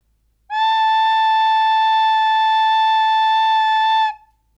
flute.wav